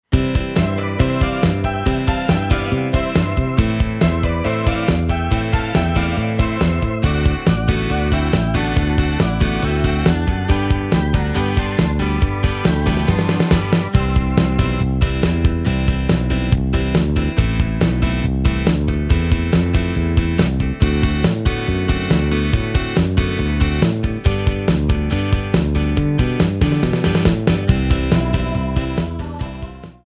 klick & play MP3/Audio demo